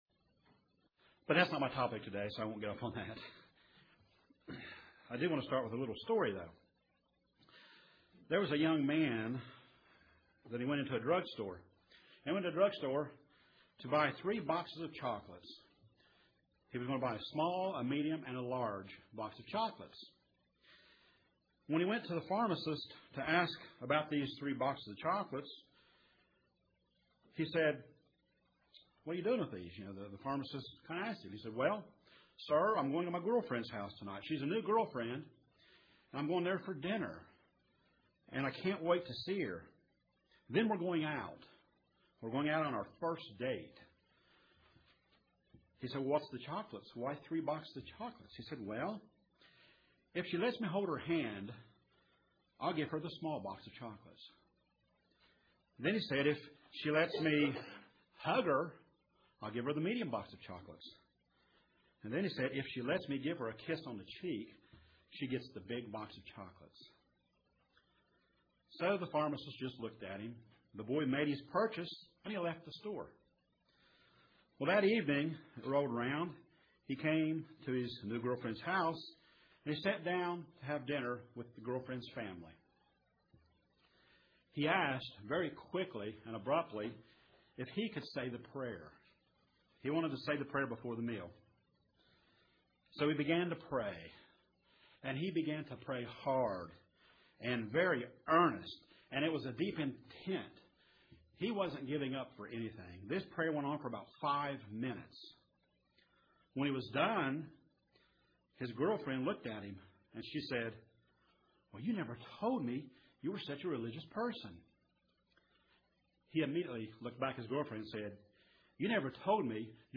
Questions, Questions,,,,,This sermon will fill in the blanks....Yes, we can still learn to pray and do it effectively....Listen , and these questions and more , will be answered